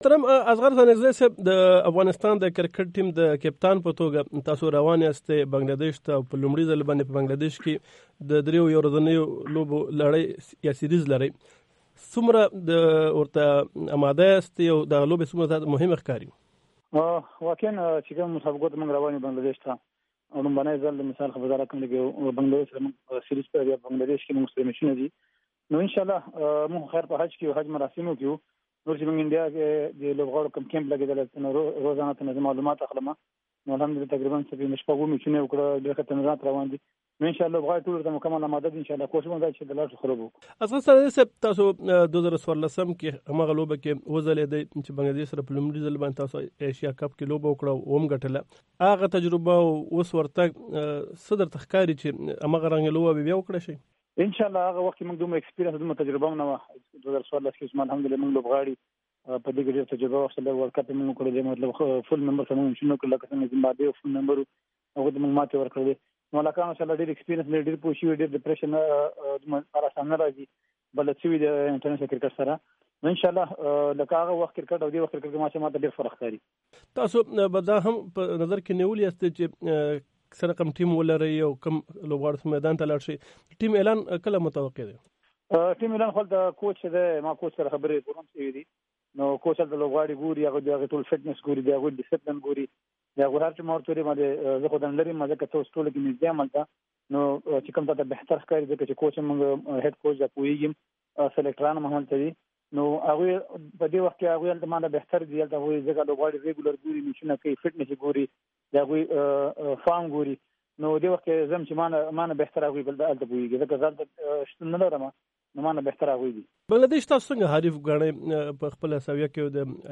د افغانستان د کرکټ د ملي ټیم کپتان اصغر ستانکزی چې د حج دپاره سعودي عرب ته تللی، د مدینې منورې نه د ټیلفوني مرکې په ترڅ کې ېې ویلي چې بنگله دیش یو قوي حریف دی او دوي به ورته یوه قوي ټیم تیاروي.
د افغانستان د کرکټ د ملي ټیم د کپتان اصغر ستانکزي سره مرکه